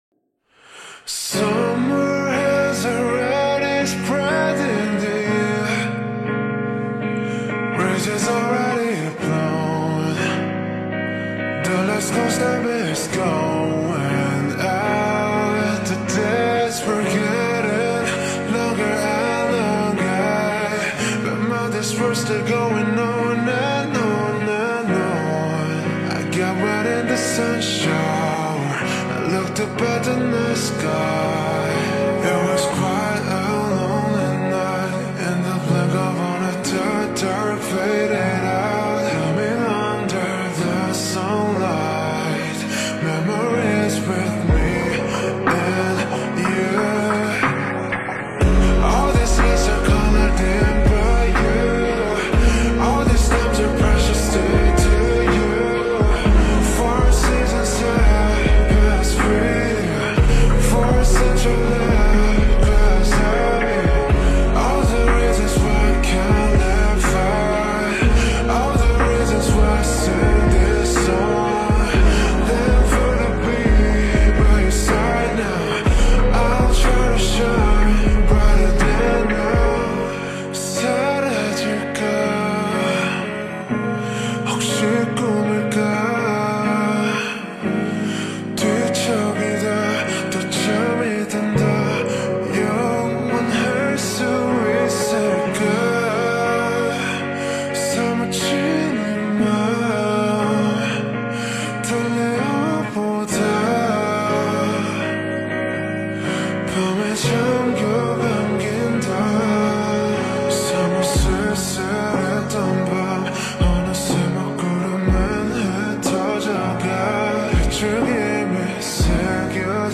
ورژن اسلو 2
slowed + reverb